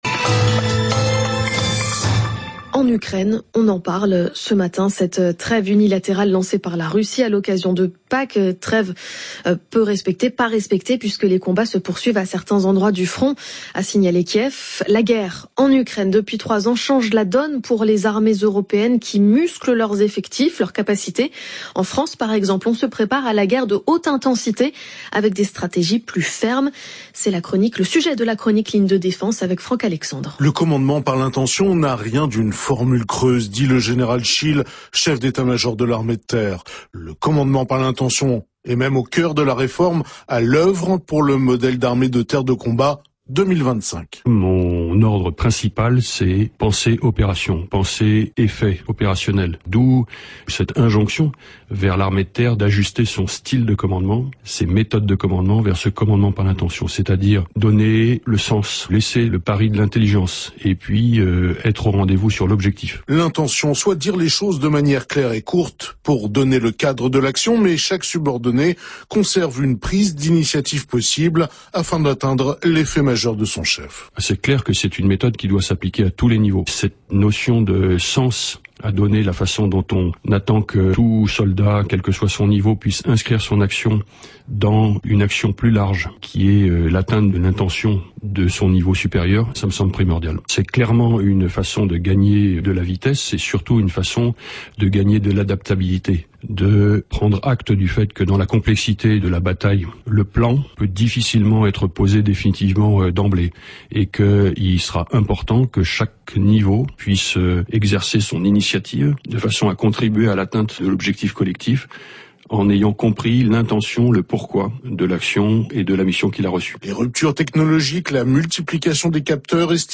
Interview du CEMAT par RFI, le 20 avril 2025, sur le commandement par l’intention
Entretien sur RFI avec le général Schill, chef d'état major de l'armée de Terre qui parle du commandement par l'intention.